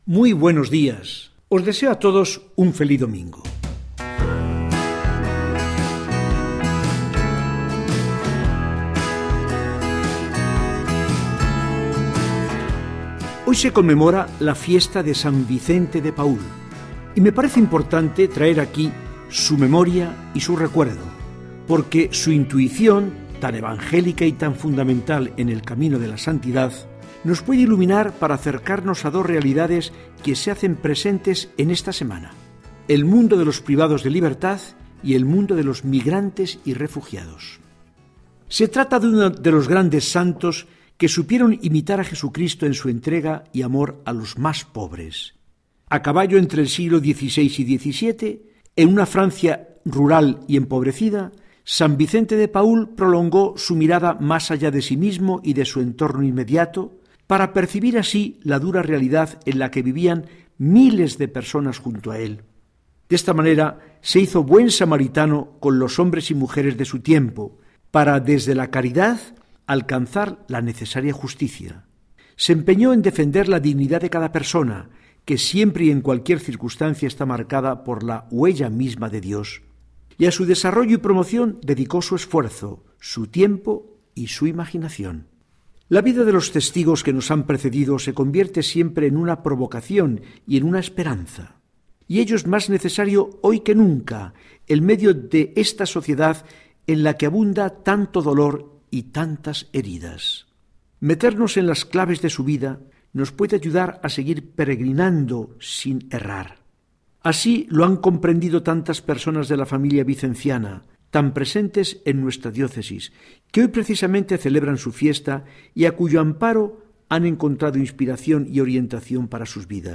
Mensaje del arzobispo de Burgos, don Fidel Herráez Vegas, para el domingo 27 de septiembre de 2020.